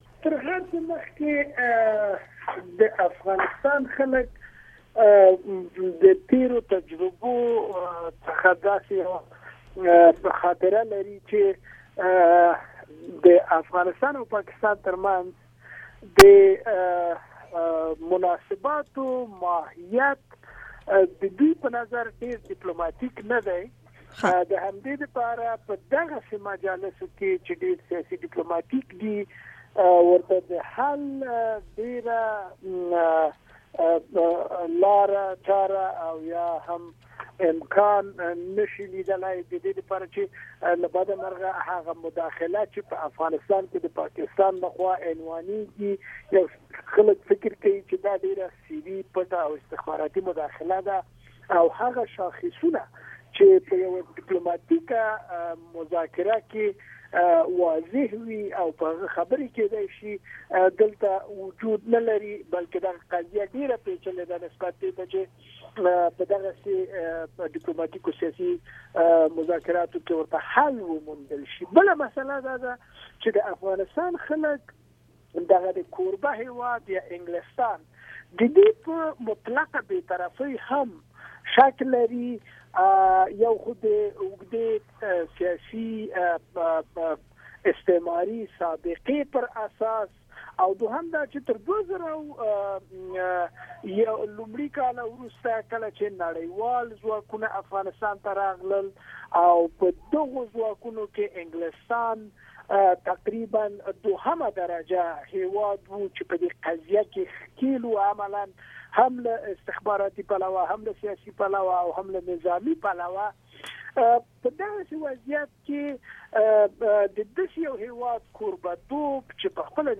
بشپړه مرکه